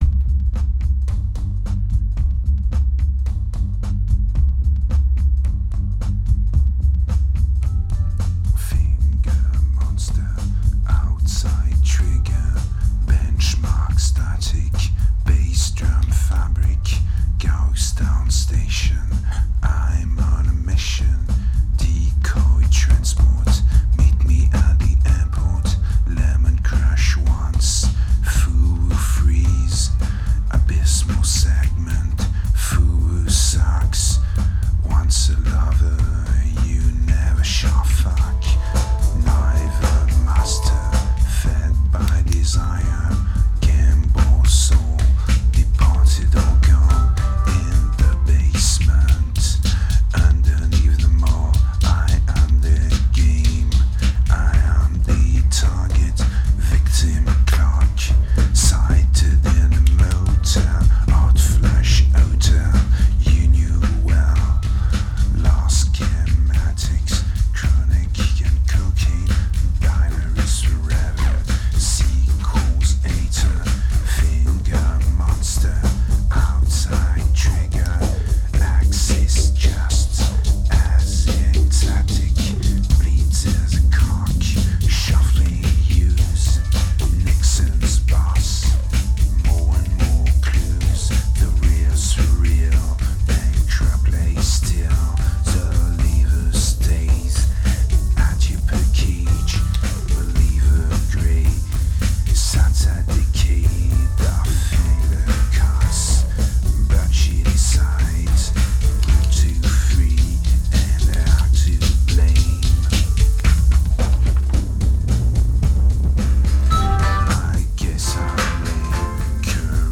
a dark album, fast and poorly recorded during late 2007
Introduction Clics Poor Sound Dirty Play Noise Ratio